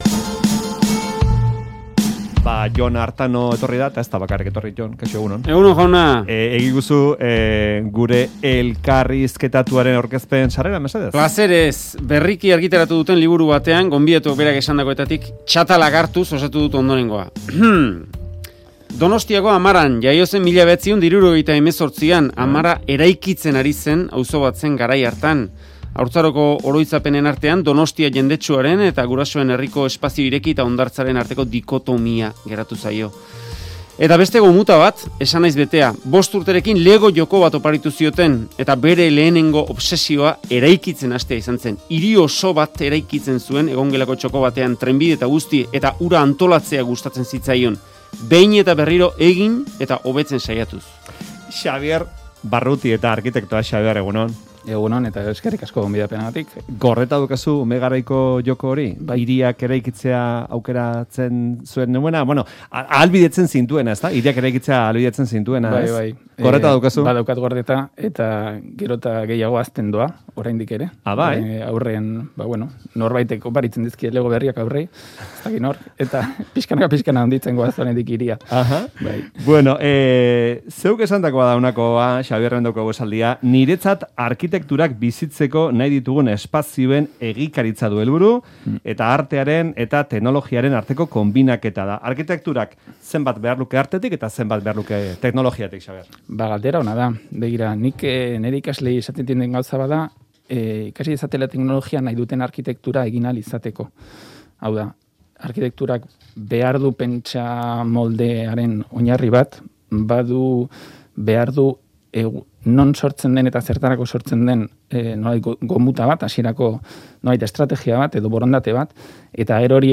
Arkitekturaz duen ikuspegiaz aritu da Euskadi Irratian.